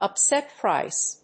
アクセントúpset príce